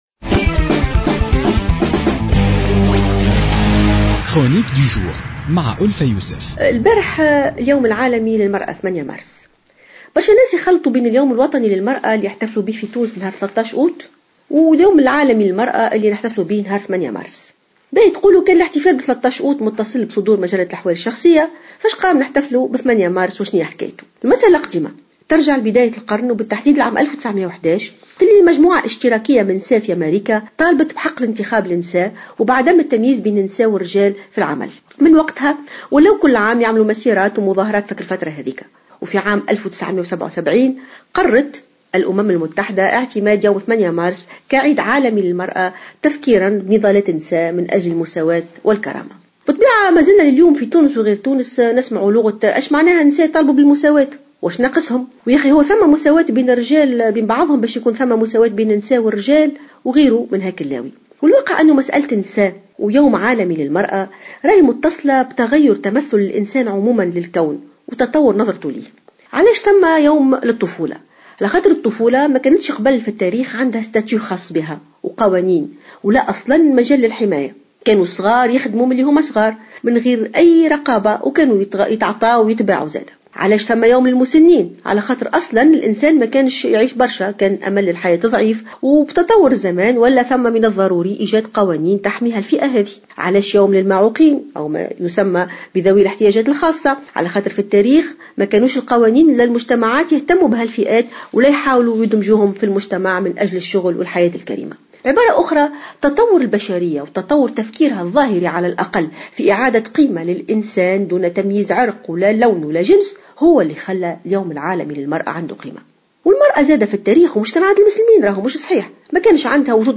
تحدّث المفكرة والجامعية ألف يوسف في افتتاحية اليوم الأربعاء عن الاحتفال باليوم العالمي للمرأة والذي يوافق يوم 8 مارس من كل سنة.